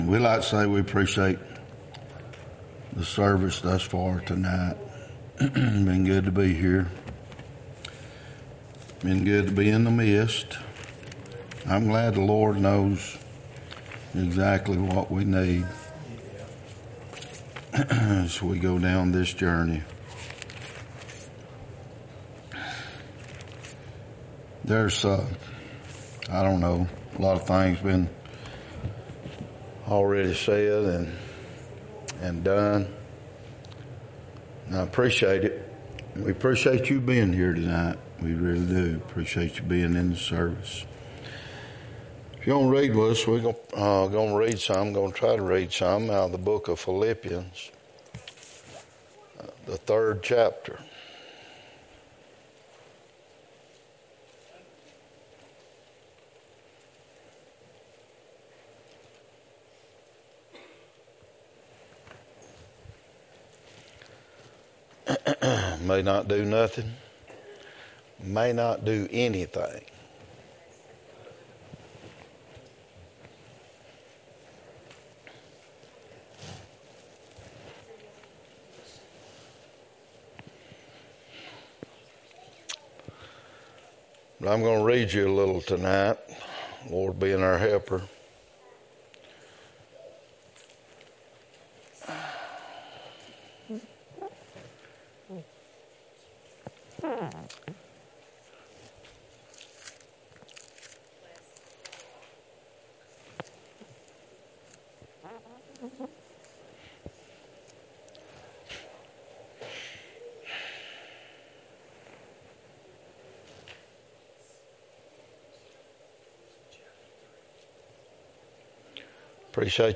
Philippians 3:1-15 1 John 2:12-13 Service Type: Wednesday night Topics